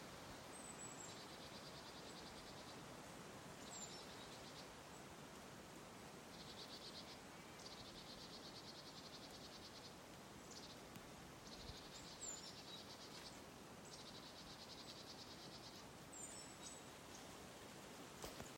Lielā zīlīte, Parus major
Administratīvā teritorijaValkas novads
StatussDzirdēta balss, saucieni